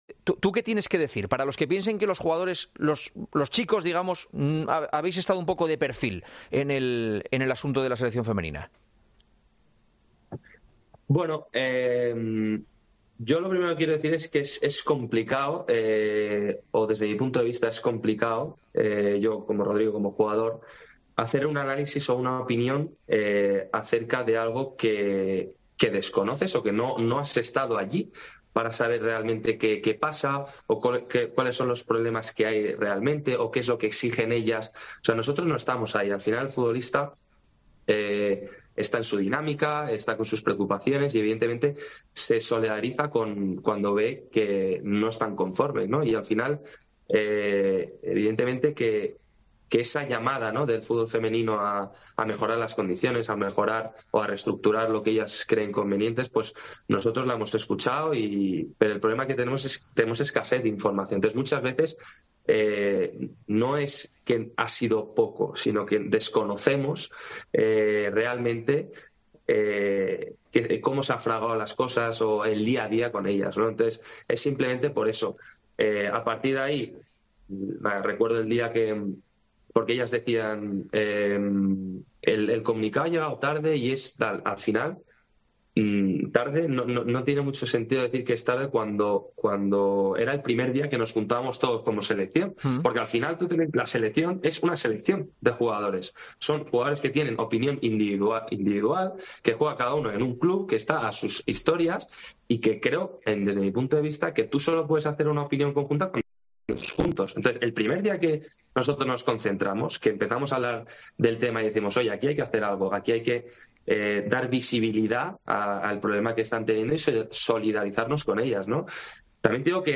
La entrevista a Rodri